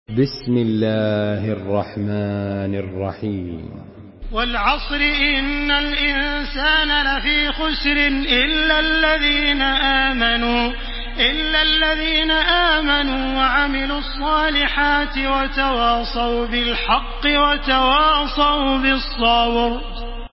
Surah Al-Asr MP3 in the Voice of Makkah Taraweeh 1434 in Hafs Narration
Murattal